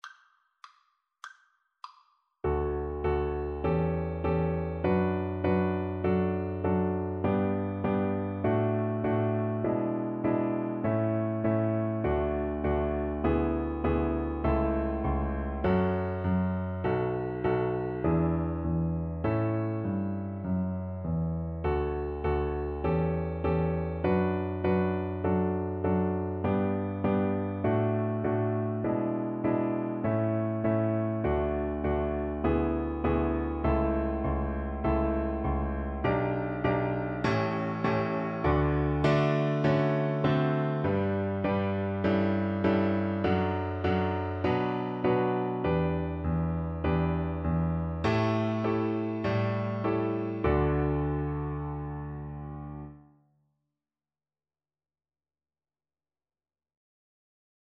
Violin version
Classical Violin